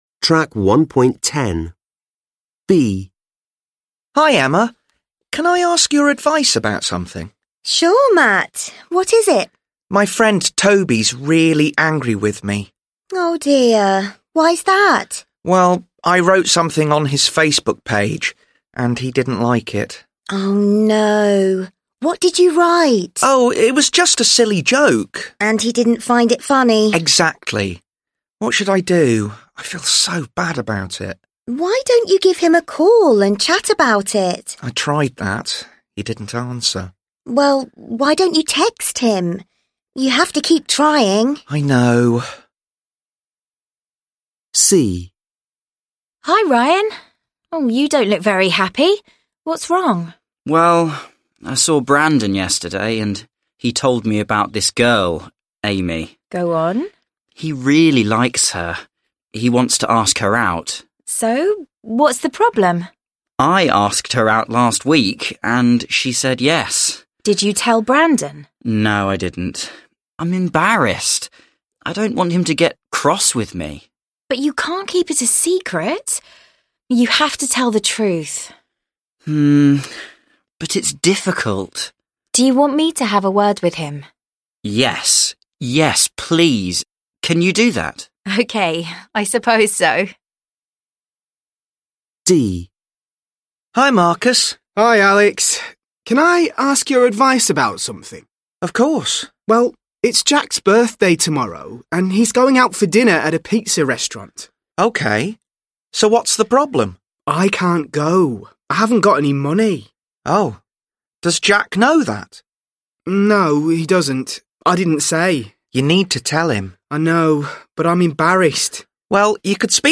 5 (trang 13 Tiếng Anh 10 Friends Global) Listen again to dialogues B, C and D. Complete the collocations (1-6) with the verbs below.